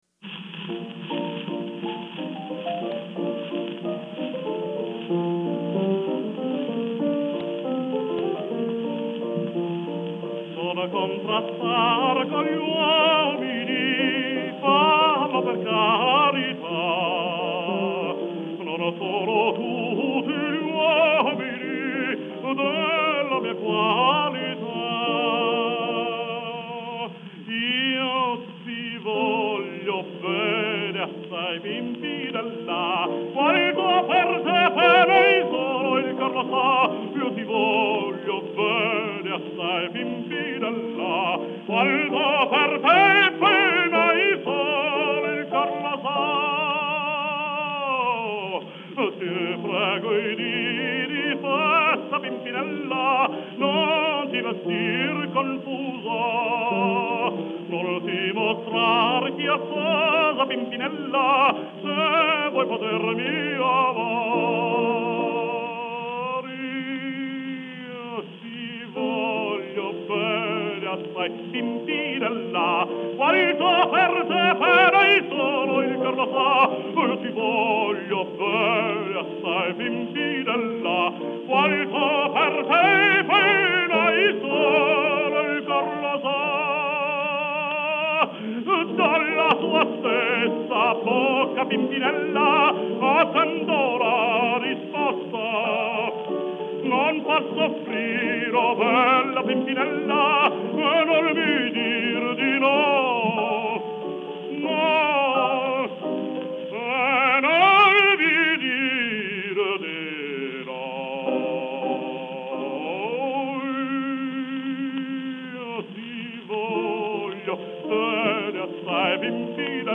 Жанр: Vocal
piano